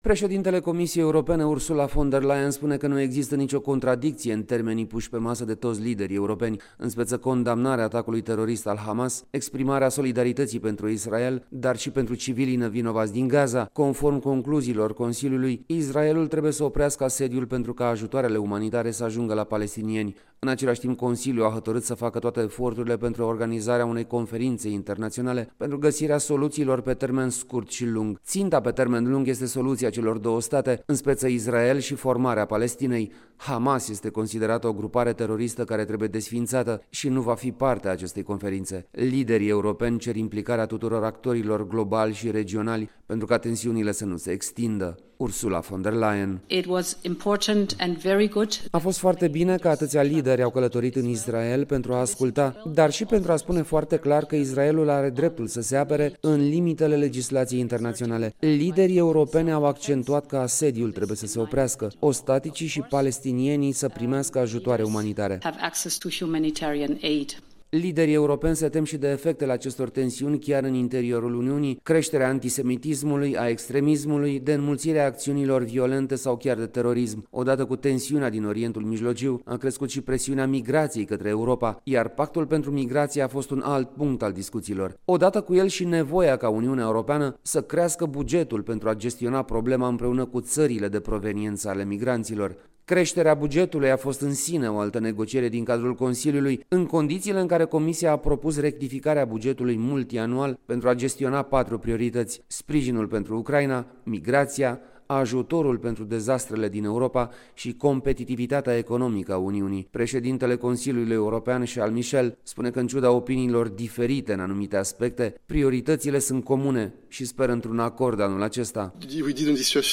transmite de la Bruxelles